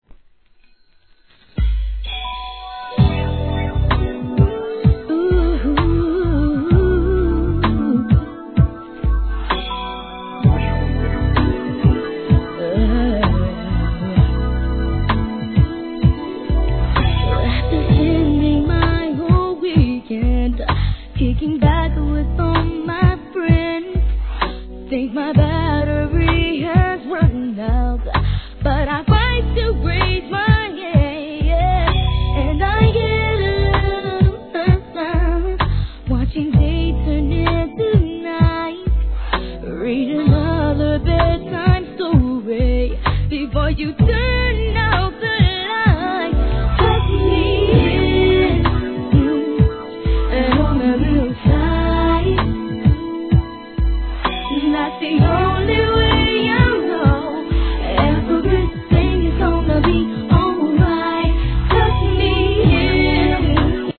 HIP HOP/R&B
1997年のSLOWナンバー!